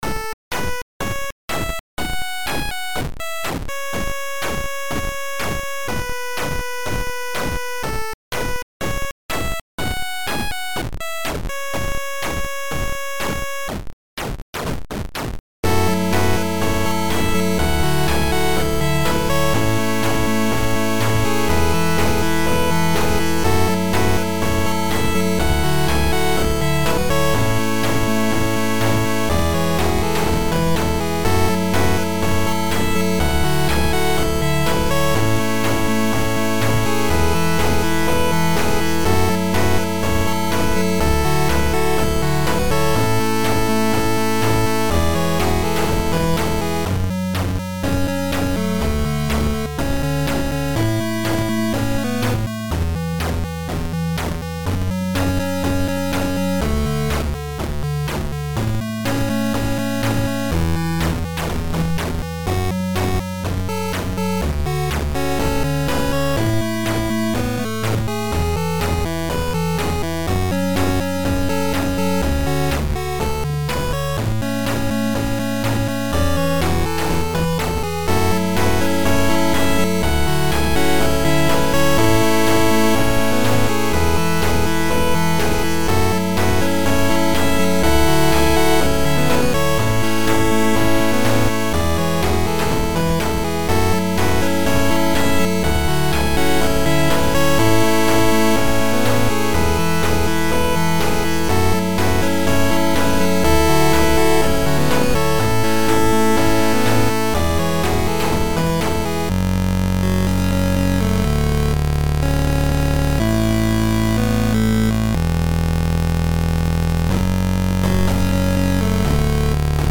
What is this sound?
Chiptunes!?!